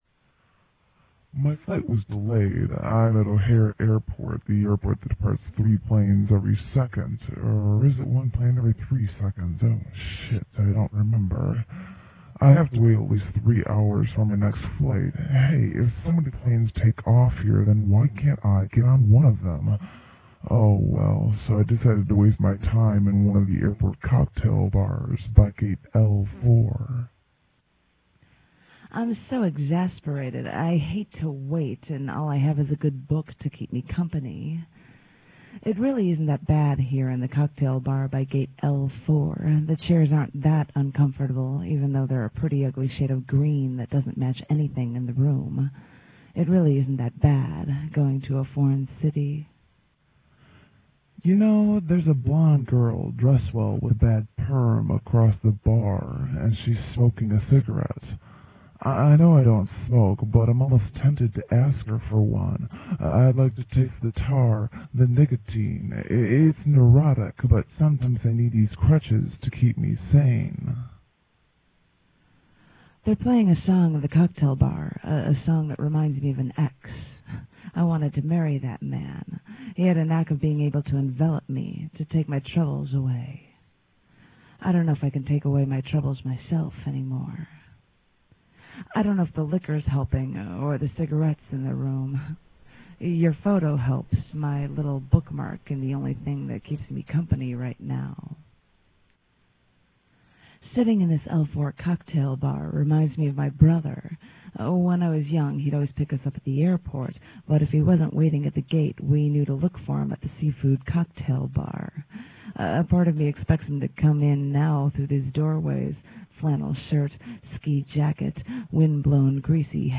the prose piece
real audio studio vocals, 2:50